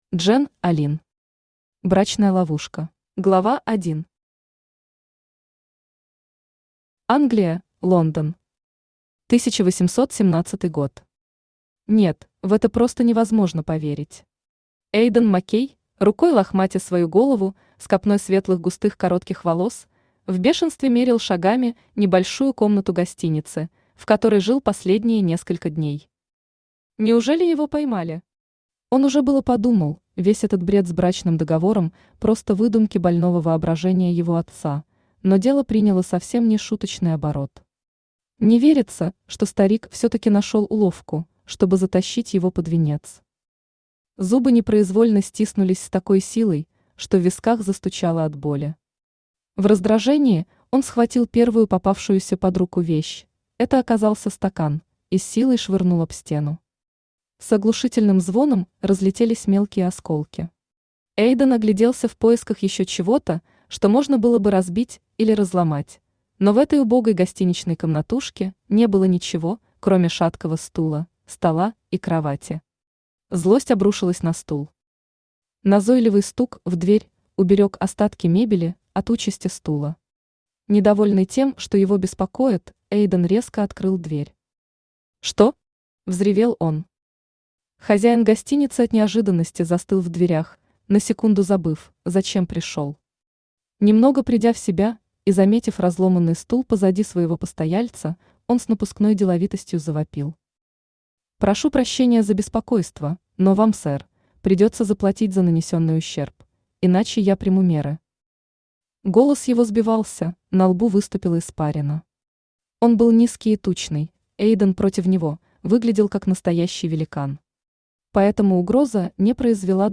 Аудиокнига Брачная ловушка | Библиотека аудиокниг
Aудиокнига Брачная ловушка Автор Джен Алин Читает аудиокнигу Авточтец ЛитРес.